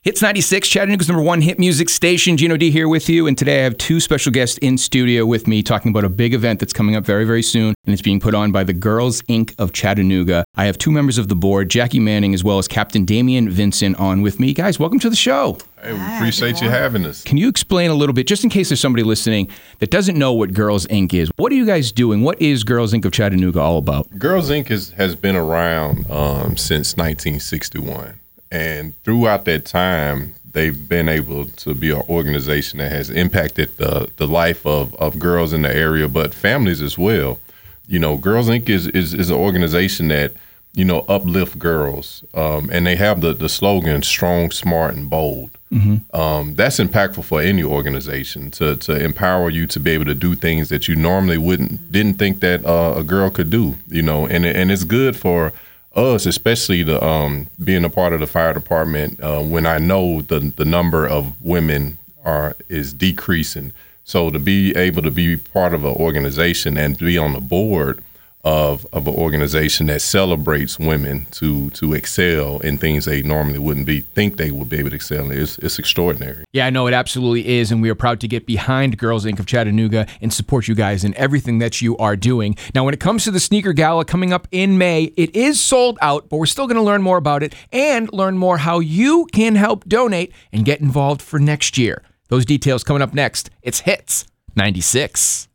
Segment-1-On-Air.wav